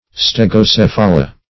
Stegocephala \Steg`o*ceph"a*la\
(st[e^]g`[-o]*s[e^]f"[.a]*l[.a]), n. pl. [NL., fr. Gr. ste`gh